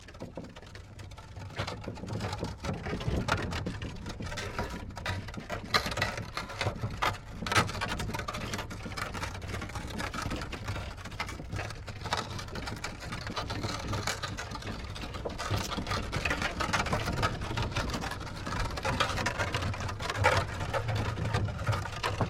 Звуки инвалидной коляски
Коляска катится по деревянным брусьям